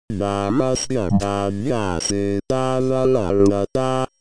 सं॒स्कृ॒त॒वाच॑नाय॒ यन्त्र॑मि॒दम्। अ॒स्मिन्ना॑स्यप्रय॒त्ना अनु॑क्रियन्ते॒नेन॒ यन्त्रे॑ण।
इ॒दं प्रयु॑क्तम् अ॒स्मिन् - Pink Trombone
"रा॒मस्य॑ भा॒र्या॑ सी॒ता वनं॑ ग॒ता।" ति वाक्यस्य ध्वनिः थम् उत्पत्स्यत ति यन्त्रचालनं